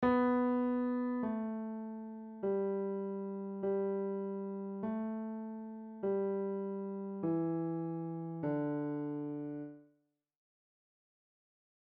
Piano Notes